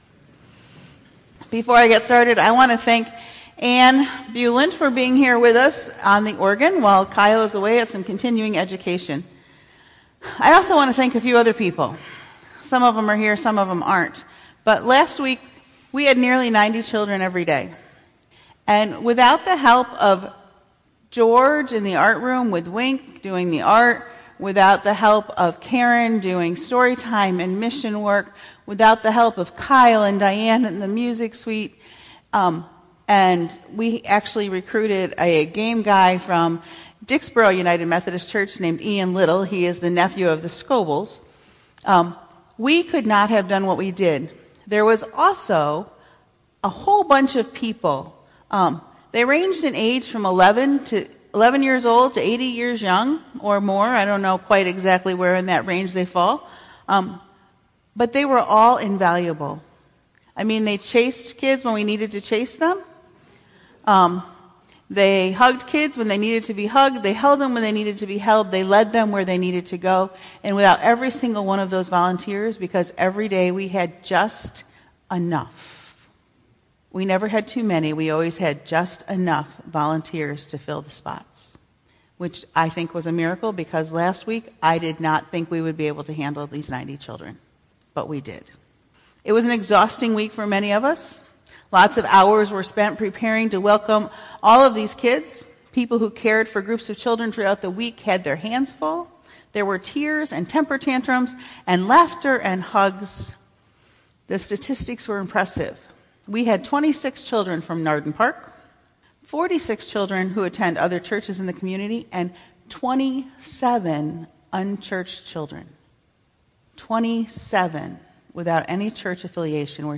July1518-Sermon.mp3